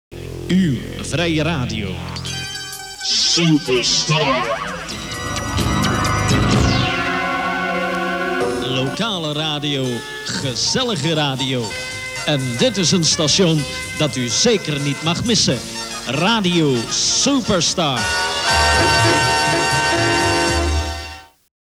Hier opname testuitzending 27 december 1980.
SupJingle1981.wav